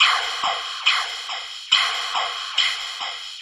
tx_perc_140_brightsplash.wav